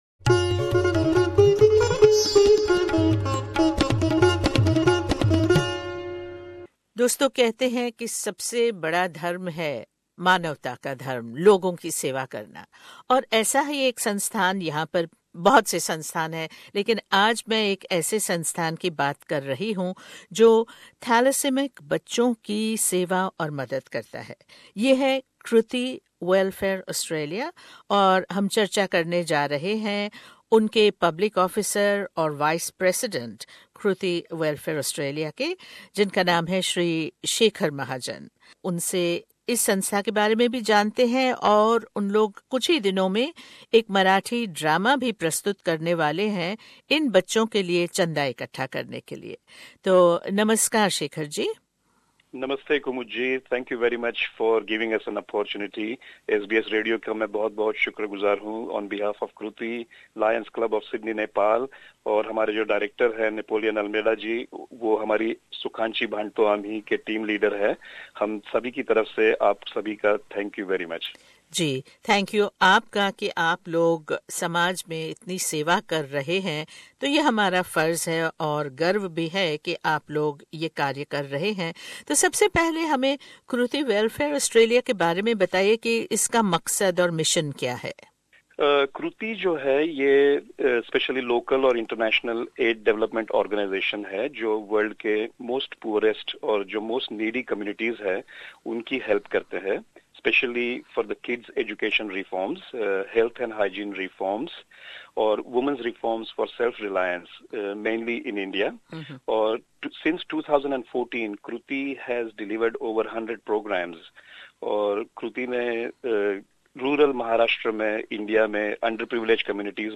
SBS Hindi recorded an exclusive interview